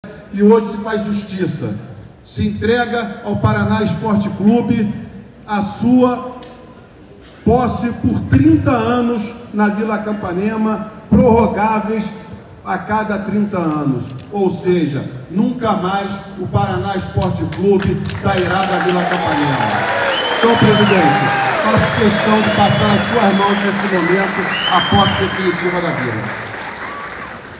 Assim que se equivocou ao inserir o ‘Esporte’ na denominação do clube, era possível ouvir as pessoas comentando sobre o erro.